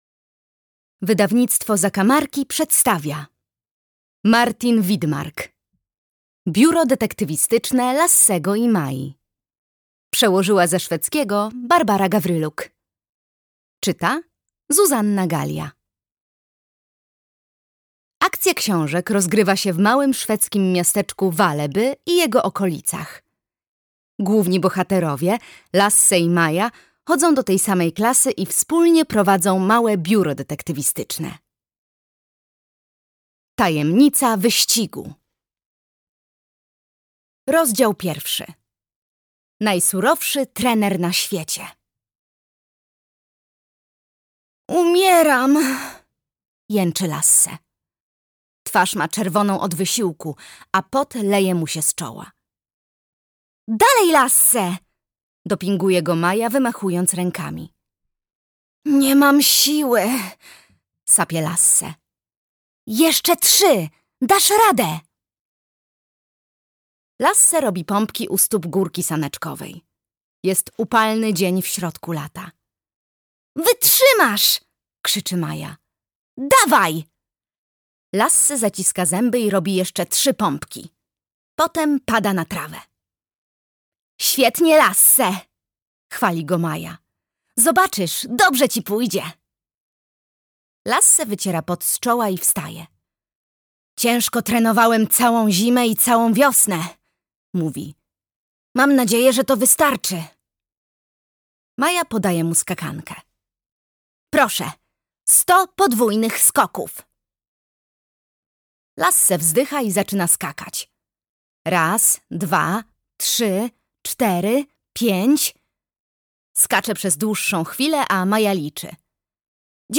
Tajemnica wyścigu - Martin Widmark - audiobook - Legimi online